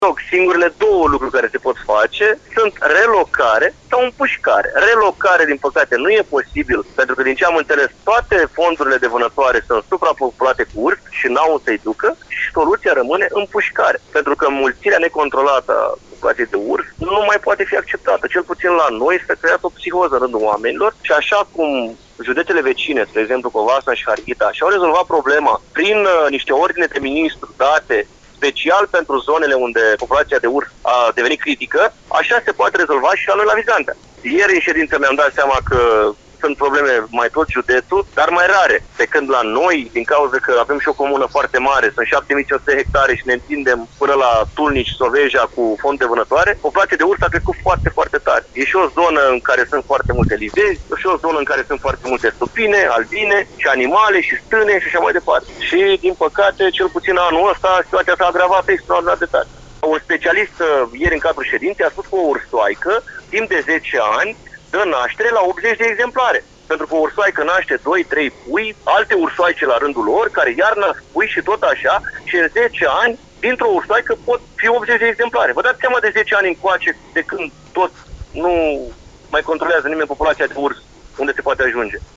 Primarul comunei Vizantea Livezi, Dragoș Ciobotaru a declarat pentru VranceaTravel că situația este tot mai greu de stăpânit, din cauza înmulțirii numărului de urși: